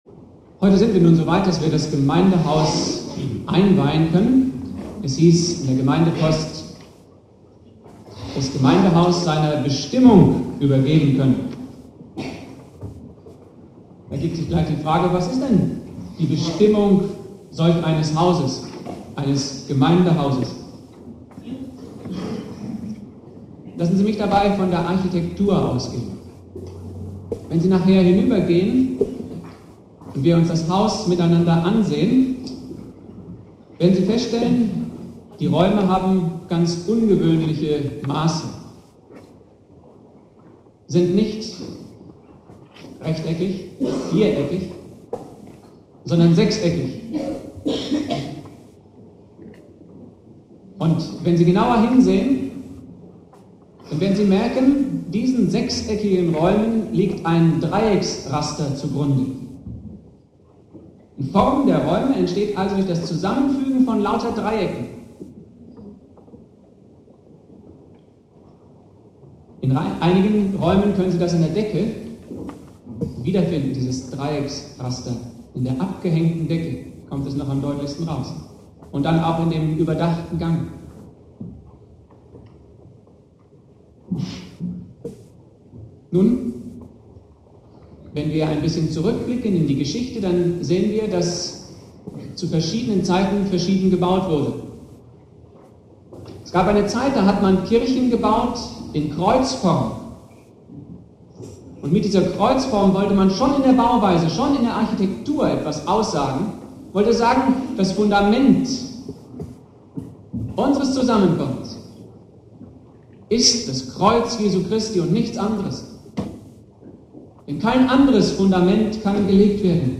Predigt
"Architektur und Glaube" - zur Einweihung des Gemeindehauses Predigtreihe